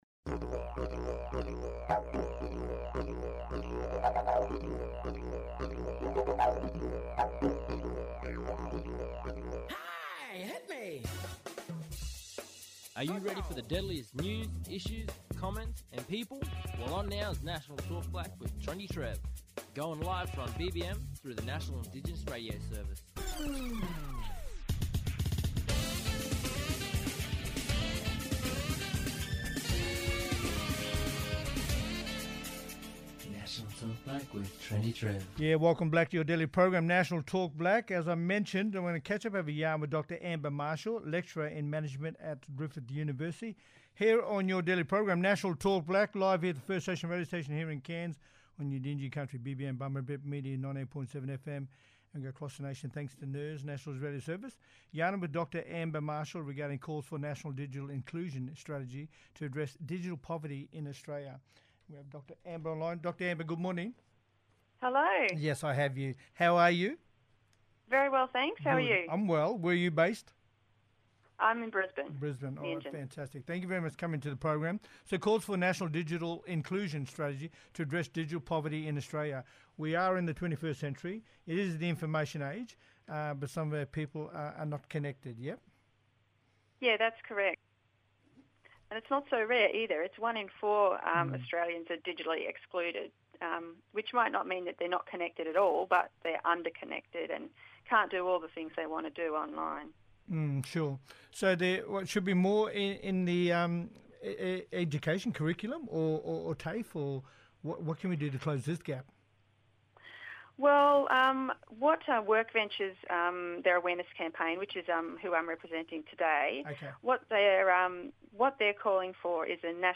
Katie Kiss, The Australian Human Rights Commissions Aboriginal and Torres Strait Islander Social Justice Commissioner, talking about ensuring the voices of First Nations people across Australia help form the agenda for the term, the Commissioner is conducting a national listening tour to hear from First Nations.